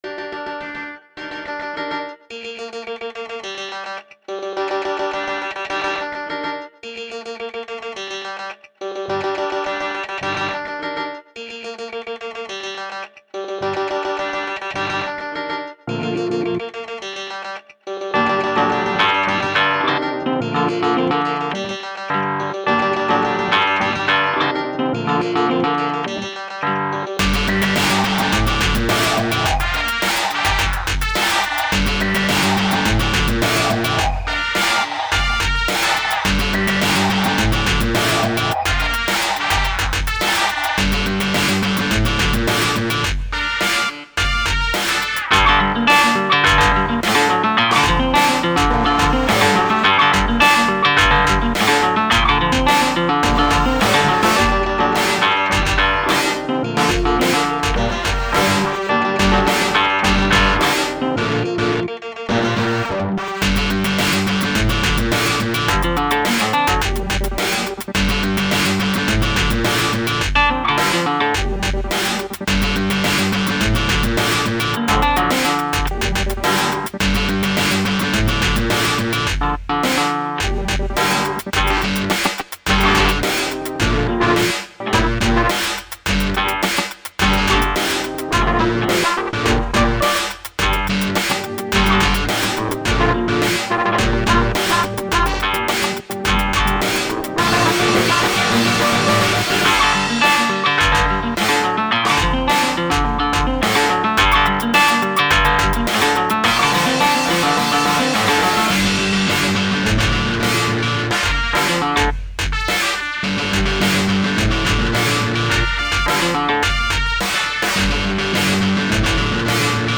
The music sped up in the middle of the dance so we had to do the steps quicker towards the end.